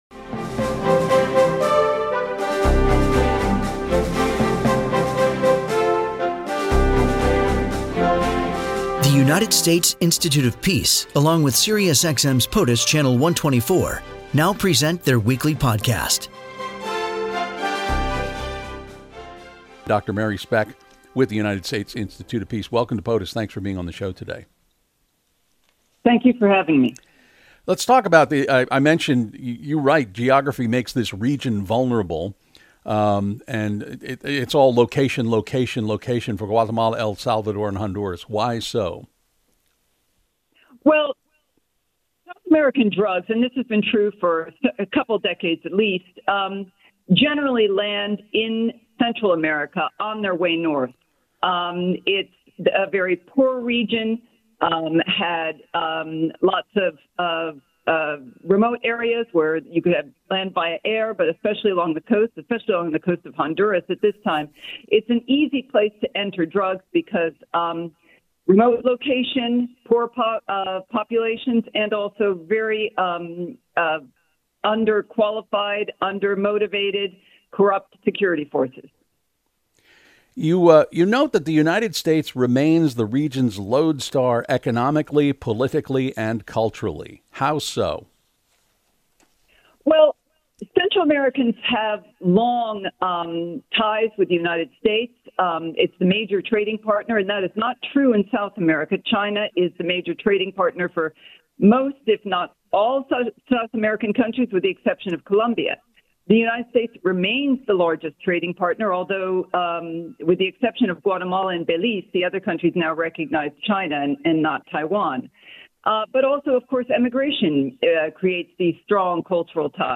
U.S. Institute of Peace experts discuss the latest foreign policy issues from around the world in this brief weekly collaboration with SiriusXM‘s POTUS Channel 124. In about 10 minutes, each episode of On Peace distills the most important aspects of a pressing peace and conflict challenge and highlights how the U.S. and international community might respond.